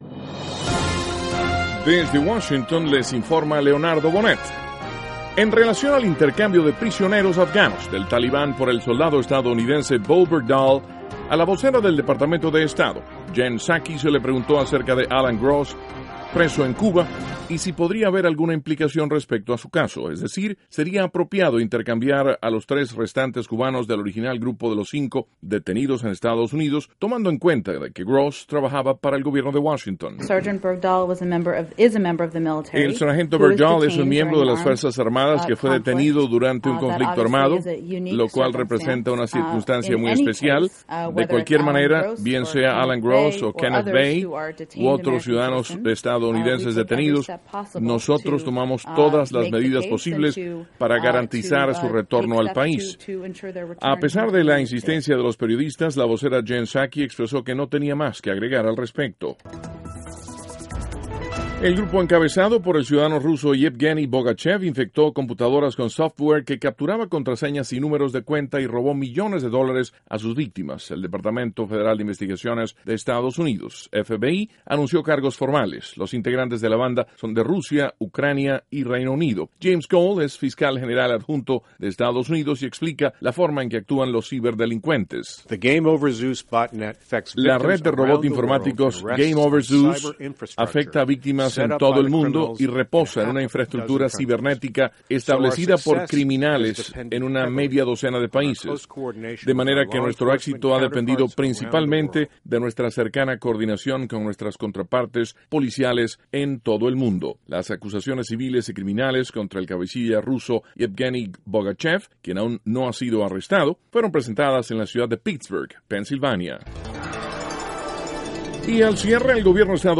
NOTICIAS - LUNES, 2 DE JUNIIO, 2014
Duración: 2:57 Contenido: 1.- Vocera Psaki habla del intercambio de prisioneros afganos por el sargento Bergdahl. (Sonido Psaki) 2.- El FBI anuncia cargos contra hacker ruso. (Sonido James Cole) 3.